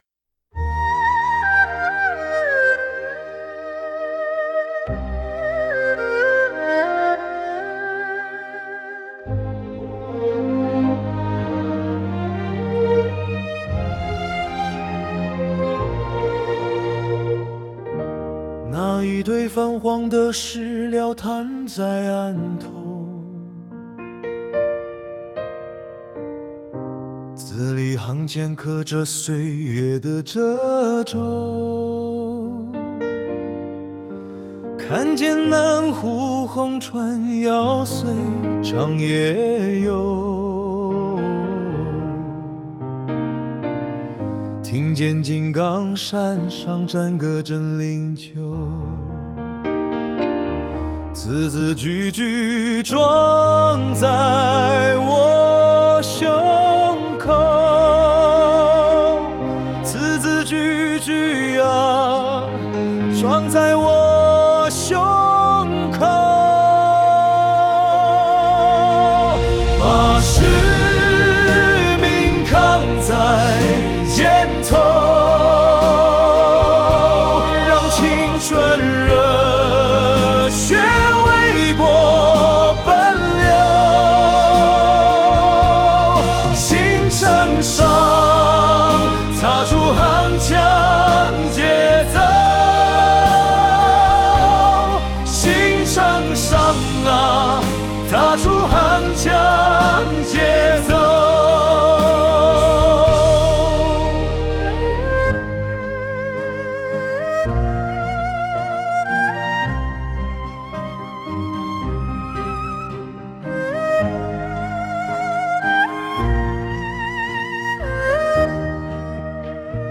科研处小组作品原创歌曲《纸间映初心》，旋律整体基调朝气蓬勃、充满力量，采用“管弦乐+民乐+流行打击乐”的多元搭配，展现出百年党史的波澜壮阔和浓郁的民族底蕴；歌词以当代青年的视角，通过翻阅史料串联起党在百年发展中的关键节点，语言朴素真挚，风格抒情豪迈，抒发出当代青年热血赤诚的爱党爱国情怀，达到了专业与党史理论高度契合的效果。